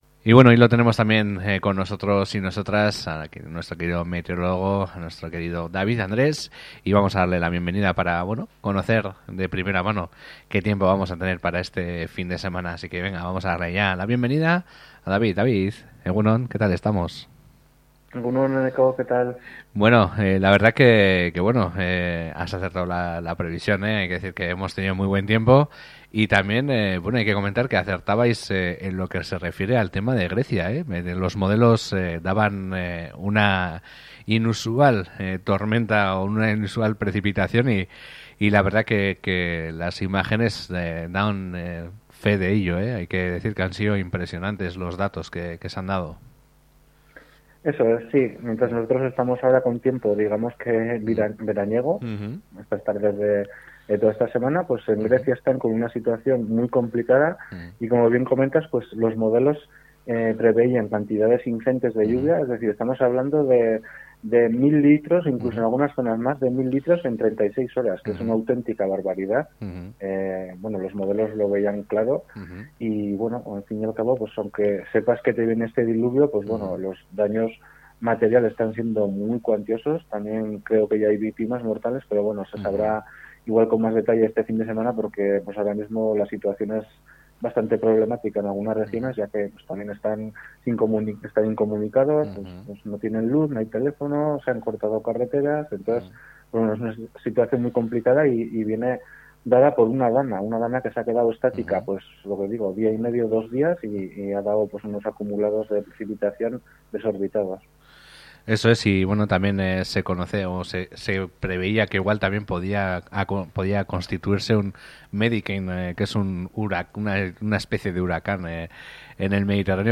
meteorologia adituarekin